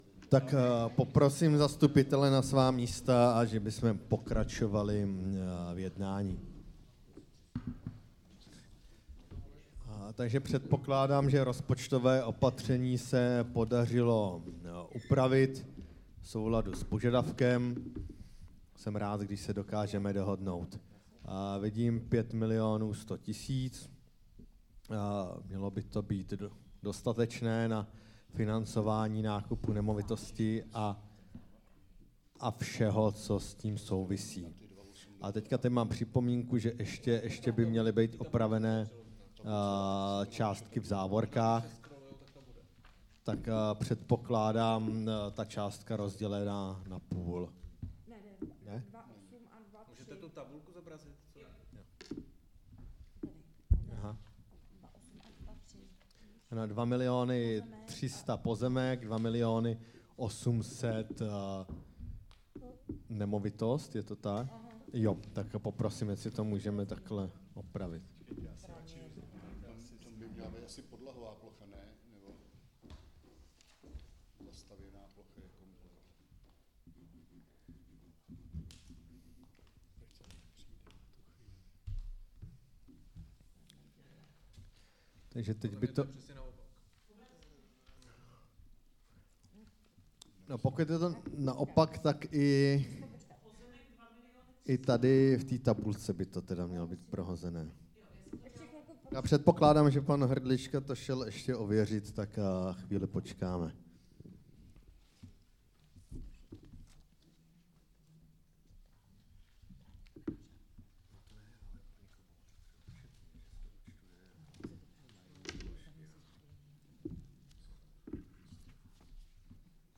21. veřejné zasedání ZMČ